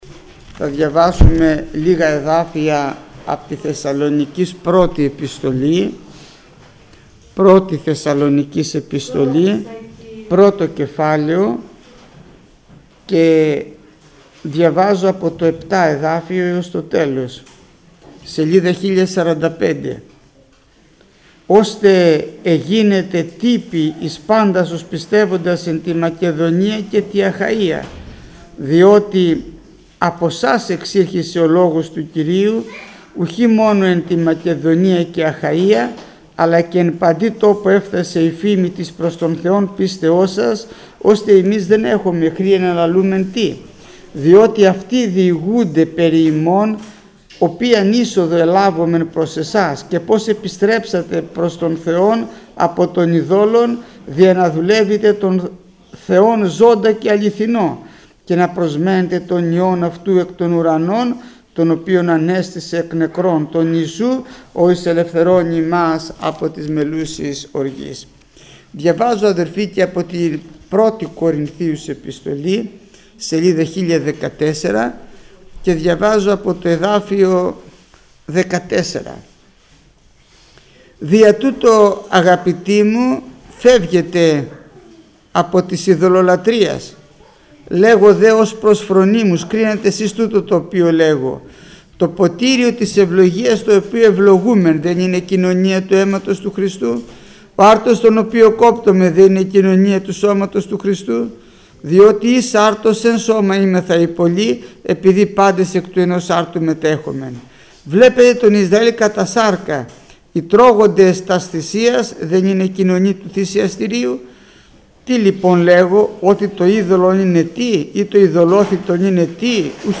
Μηνύματα Θείας Κοινωνίας
ΜΗΝΥΜΑΤΑ ΠΡΙΝ ΤΗ ΘΕΙΑ ΚΟΙΝΩΝΙΑ